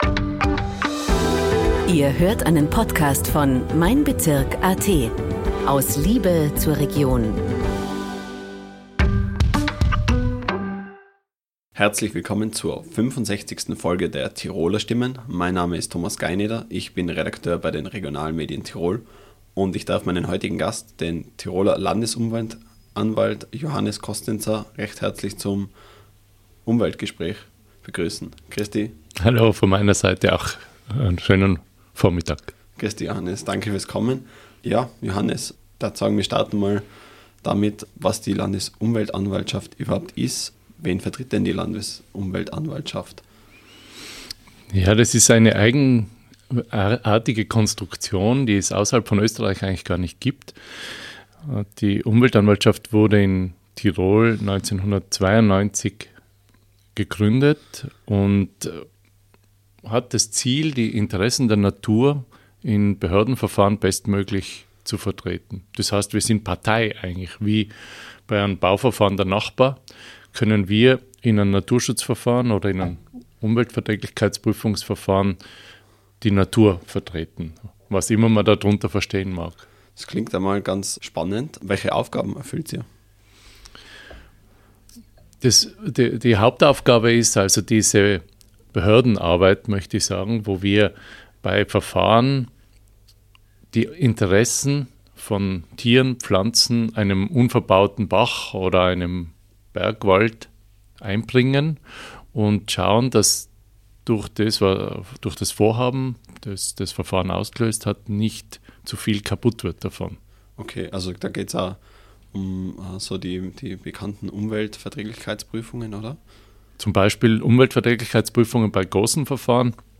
In der 65. Folge des TirolerStimmen-Podcasts ist Landesumweltanwalt Johannes Kostenzer zu Gast. Im Umwelt-Gespräch spricht er über das Thema Boden in Tirol, über den Alltag als Landesumweltanwalt und er erklärt, wie man überhaupt Umweltanwalt wird.